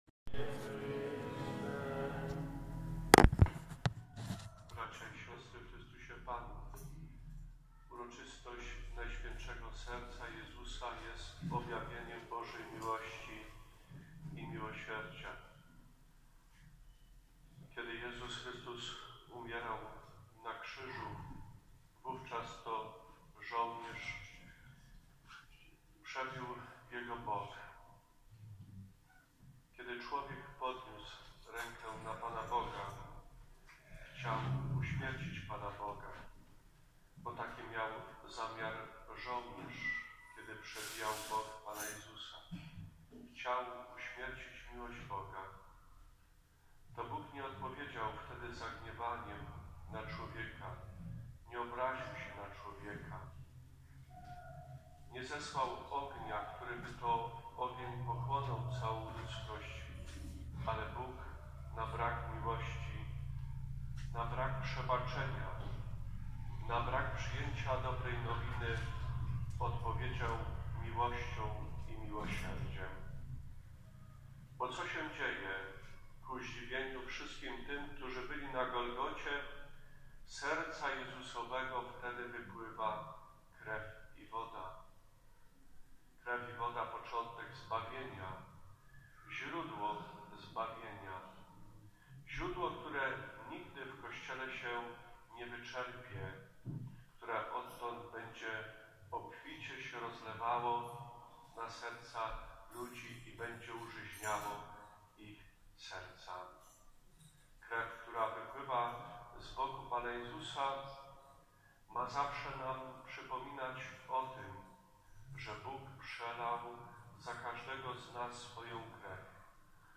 28 czerwca obchodzili�my najwi�ksze �wi�to parafialne - odpust.
kt�re nawet po �mierci tak bardzo ludzi ukocha�o. Ca�o�� kazania mo�na ods�ucha� tutaj>>
kazanie odpust.m4a